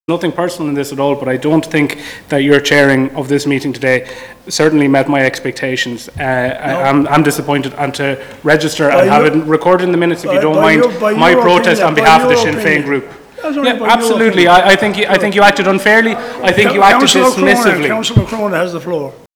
A back-and-forth followed between Cllr. Conor McGuinness and Mayor of Waterford City & County, Cllr. John O’Leary (Fianna Fail) toward the end of the meeting.
OLeary-McGuinness-1.wav